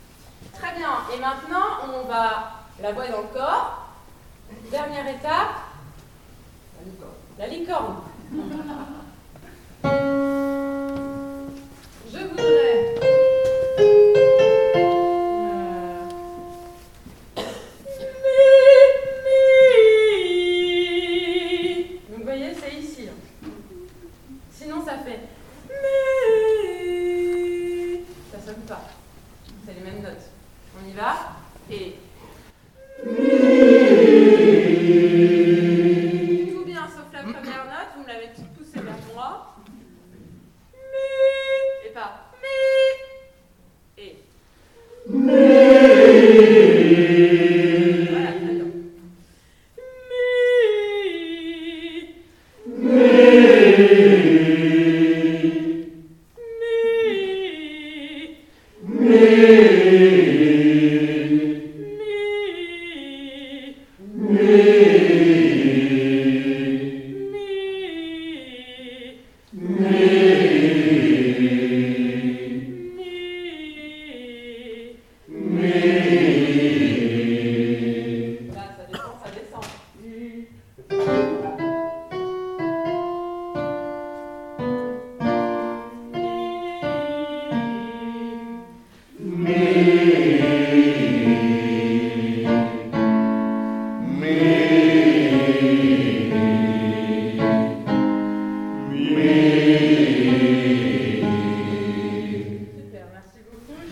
Echauffement vocal
Ces enregistrements au format MP3 ont été réalisés lors de la répétiton du 22 septembre 2025.
Echauffement 3 - La licorne (toutes voix)
3 - LA LICORNE -  Pour les chanteurs, un exercice efficace consiste à pratiquer des vocalises, soit des gammes ascendantes et descendantes, en utilisant des voyelles telles que "A," "E" et "O".